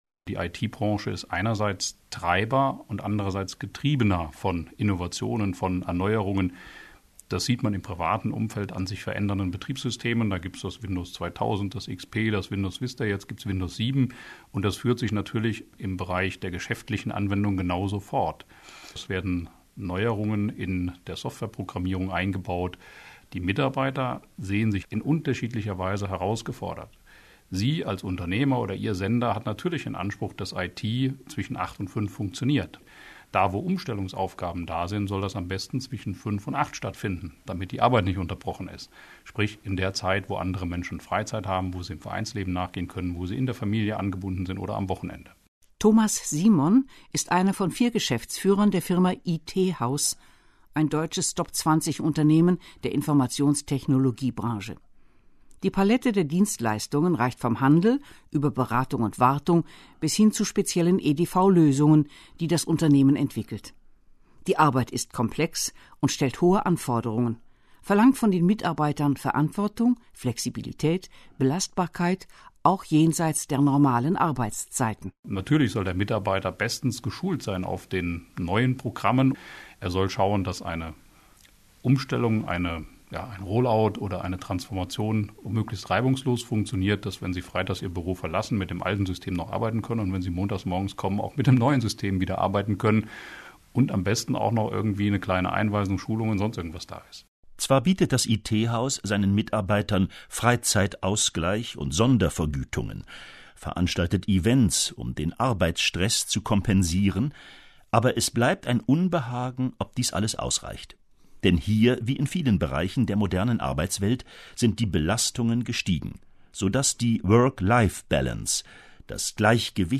Medium: Interview, Deutschlandfunk, 01.04.2010